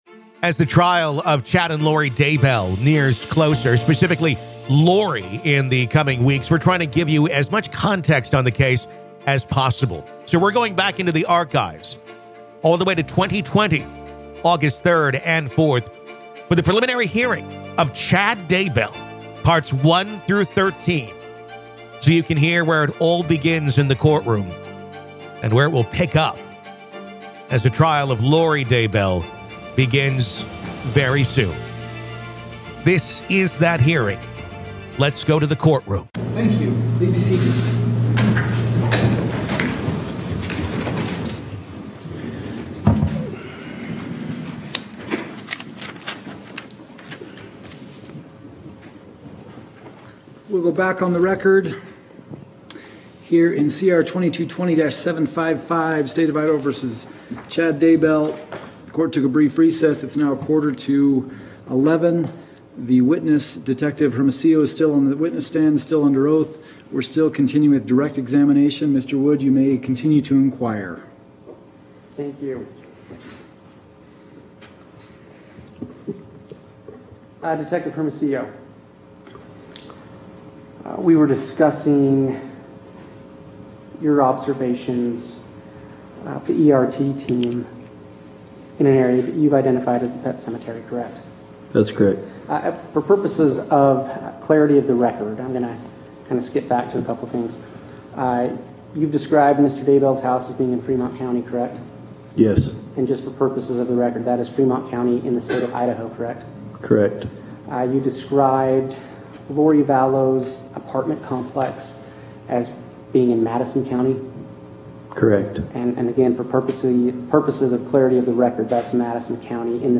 Listen To The Full Preliminary Hearing Of Chad Daybell, Part 3
This is the complete preliminary hearing of Chad Daybell, originally recorded August 3rd and 4th of 2020.